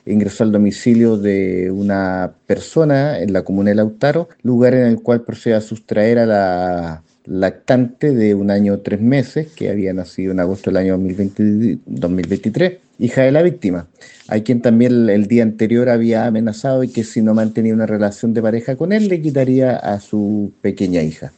Las diligencias desplegadas por personal de la Primera Comisaría de Carabineros de Lautaro, permitieron ubicar al hombre y recuperar a la lactante, tal como lo precisó el fiscal del Ministerio Público, Miguel Ángel Velásquez.